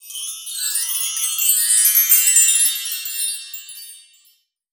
magic_sparkle_chimes_02.wav